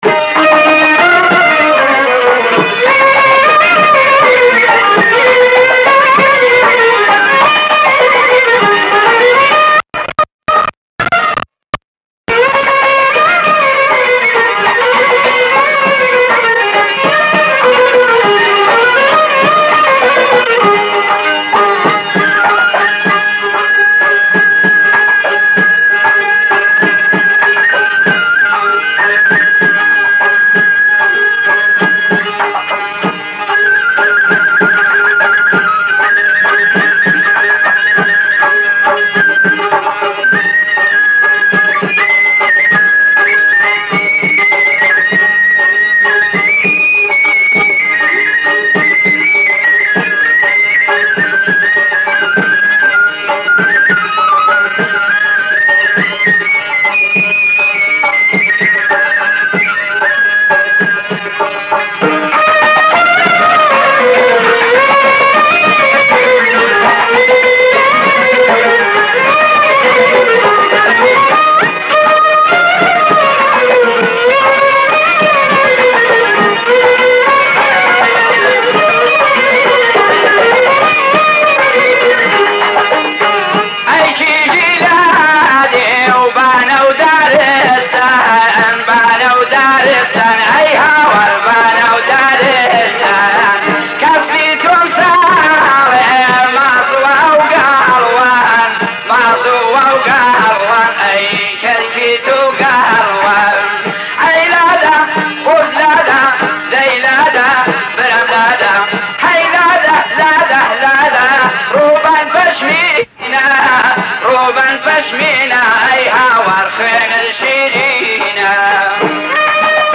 موسیقی کردی